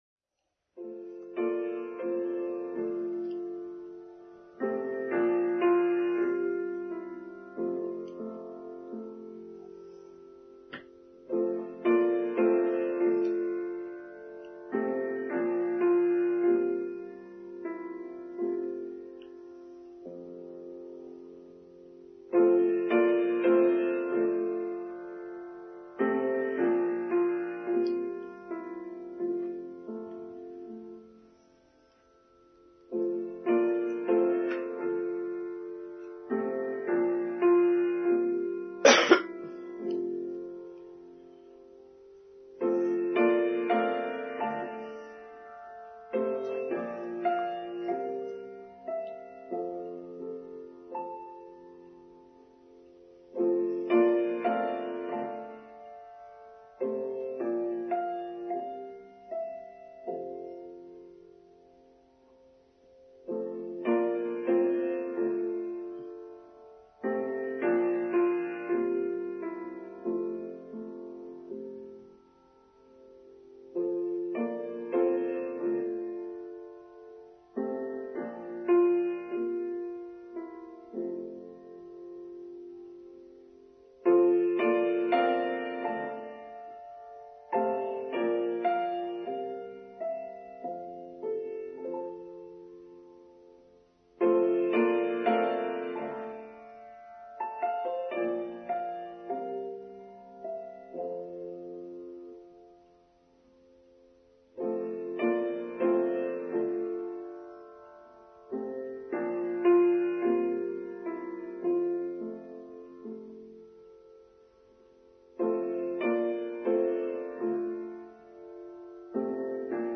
From Tolerance to Inclusivity: Online Service for Sunday 2nd October 2022